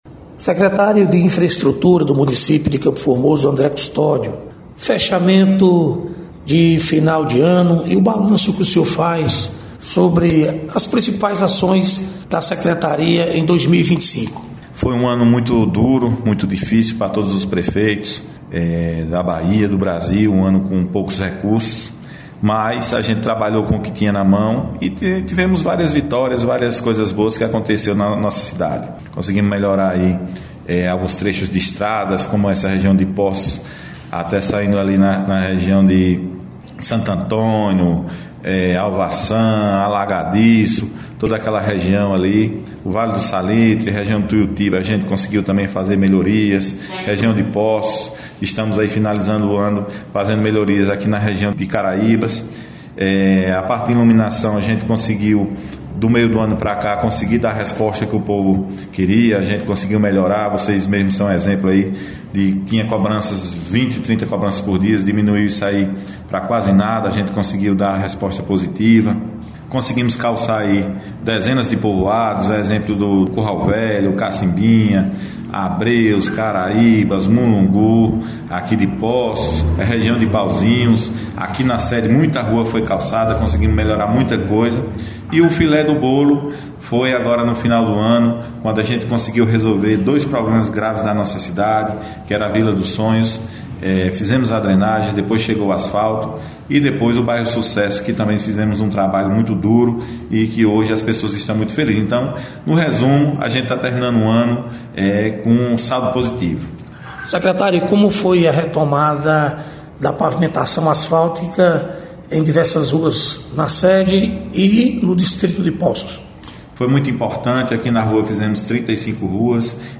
Secretário de infraestrutura de Campo Formoso, André Cústodio – Ações prioritárias da secretaria